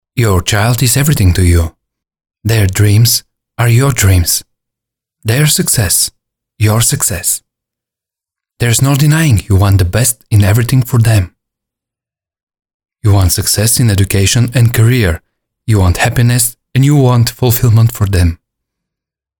Male
Adult (30-50)
Natural, Pure, Clean, Fresh.
VOICE ACTOR DEMOS
Studio Quality Sample